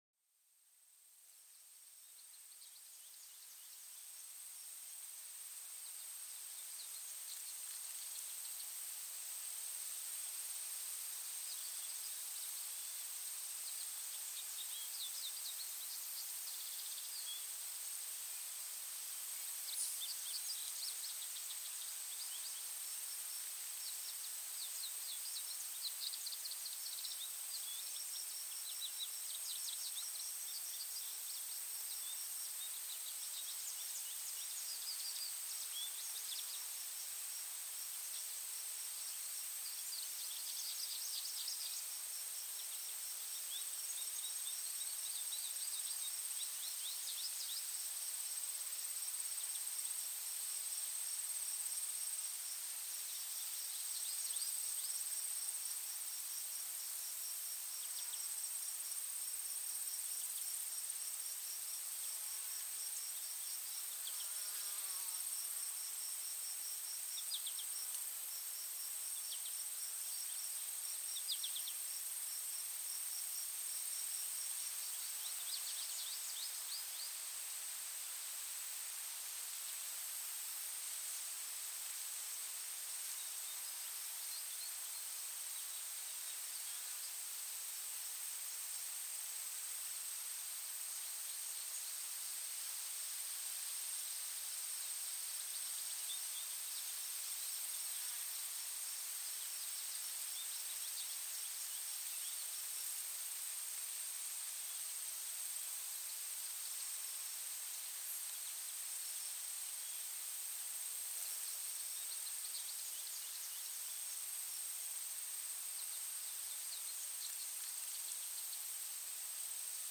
SONIDOS DE LA NATURALEZA PARA LA RELAJACIÓN